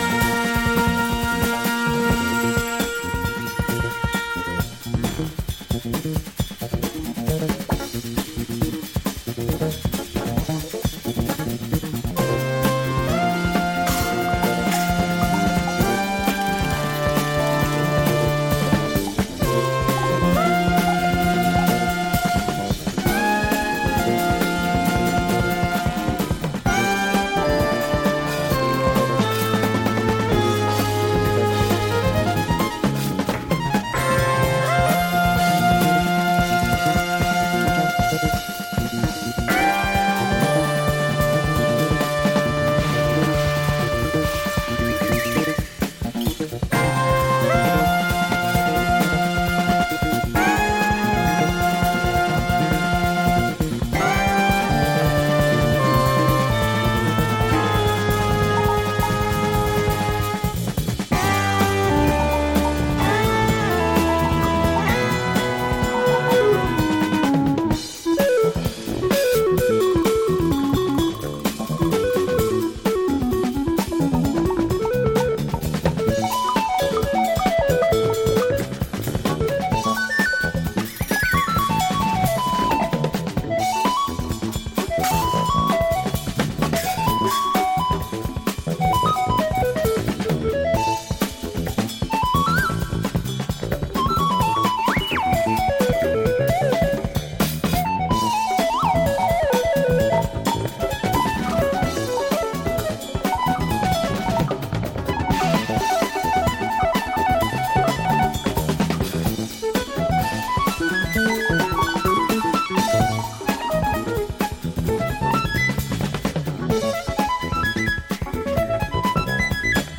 Jazz